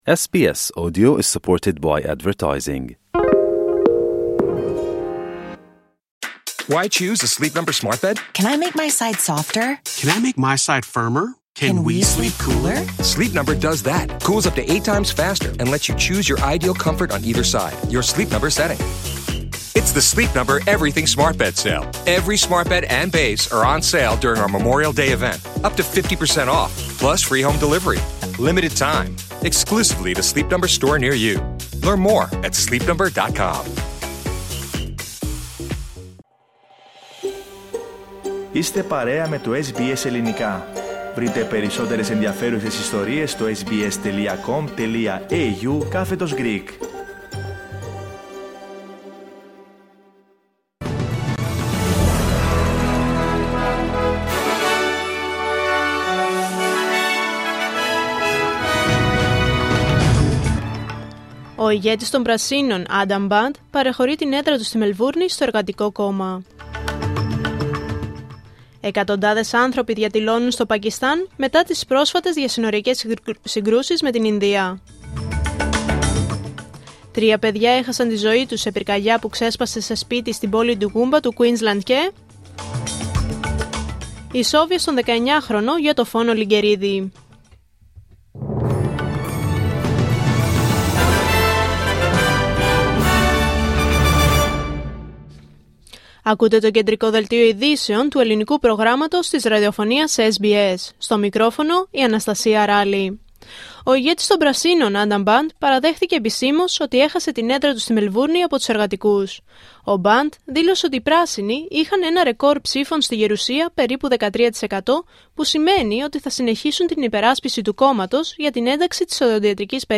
Δελτίο Ειδήσεων Πέμπτη 08 Μαΐου 2025